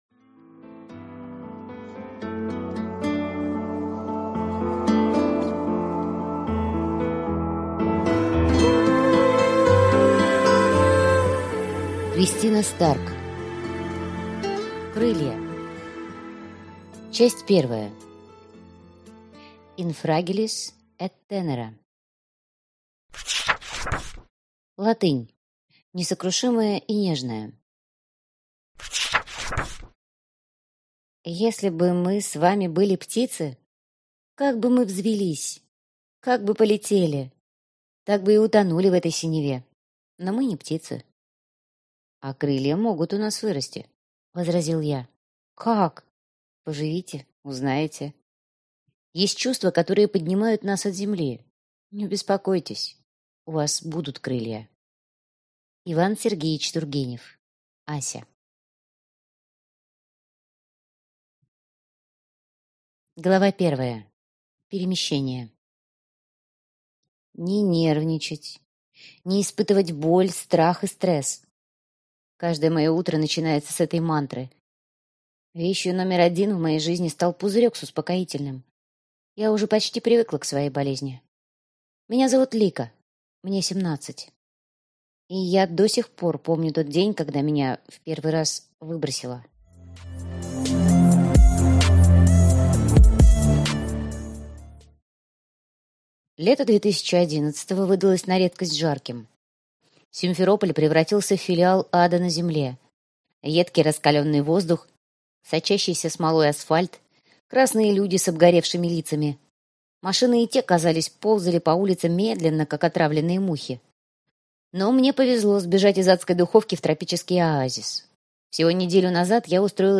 Аудиокнига Крылья - купить, скачать и слушать онлайн | КнигоПоиск